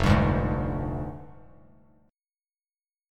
Fm6add9 chord